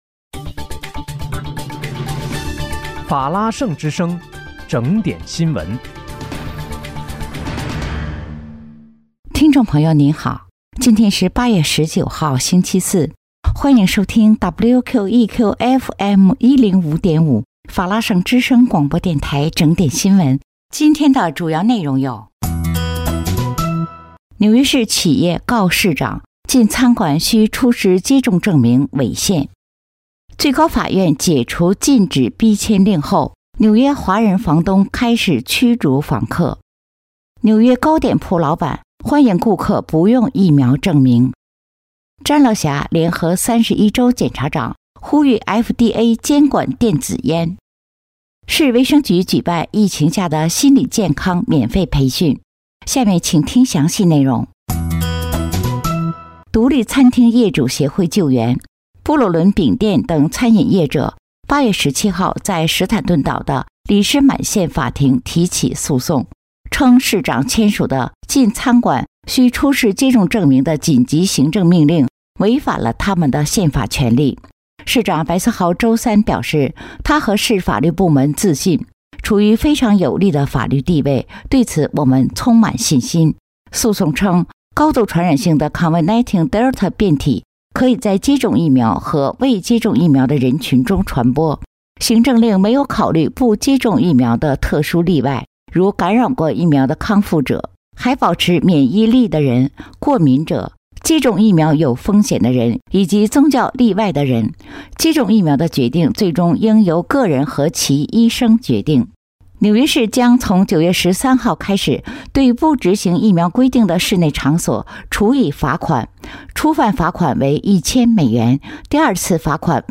8月19日（星期四）纽约整点新闻
听众朋友您好！今天是8月19号，星期四，欢迎收听WQEQFM105.5法拉盛之声广播电台整点新闻。